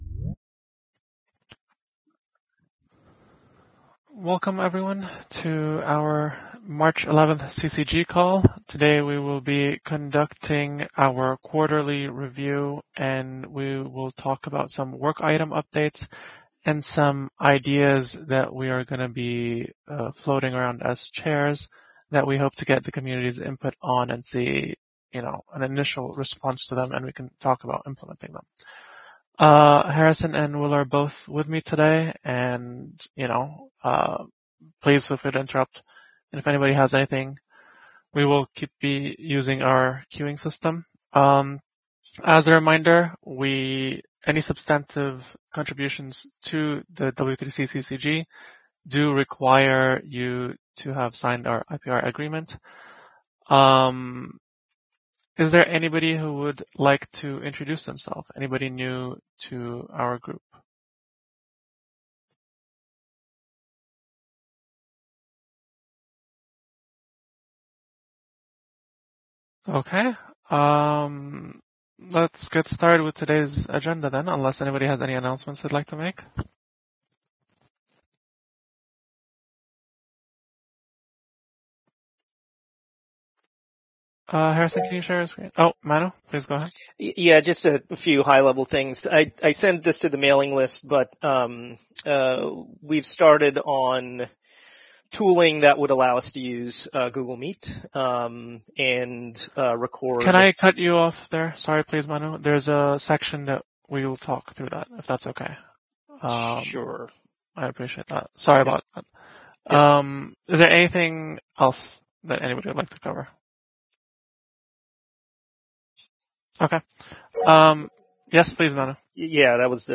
W3C CCG Weekly Teleconference